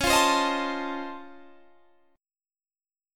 C#+M9 Chord
Listen to C#+M9 strummed